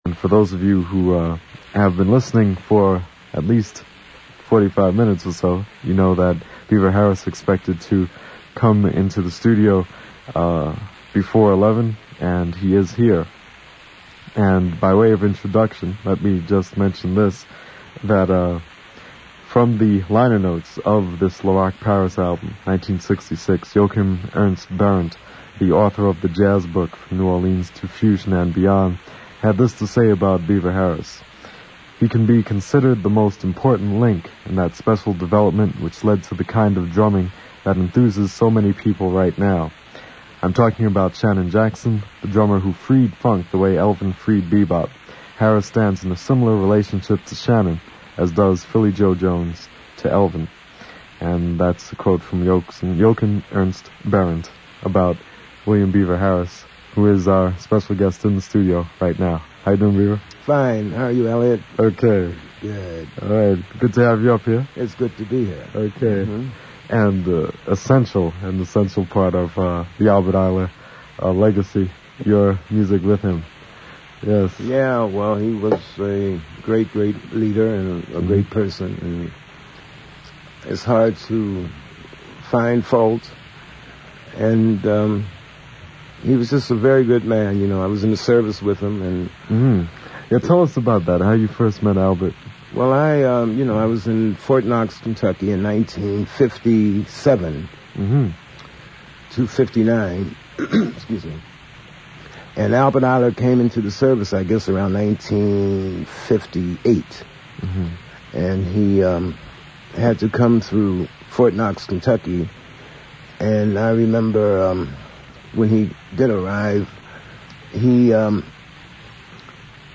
Beaver Harris Interview (47 mins. 21.8 mb.)